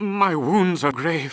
vs_fxanxxxx_heal.wav